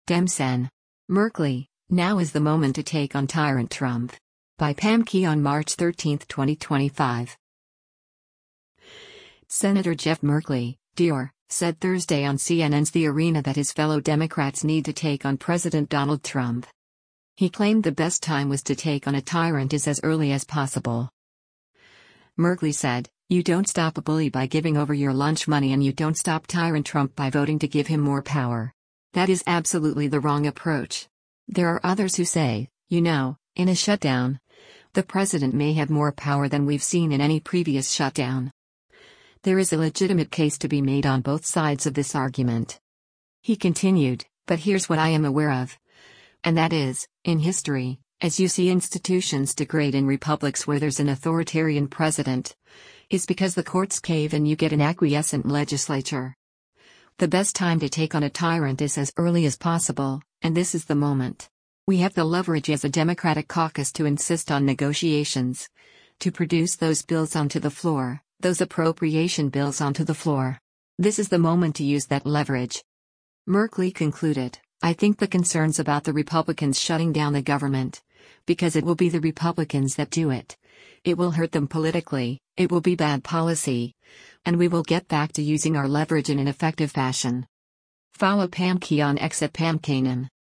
Senator Jeff Merkley (D-OR) said Thursday on CNN’s “The Arena” that his fellow Democrats need to “take on” President Donald Trump.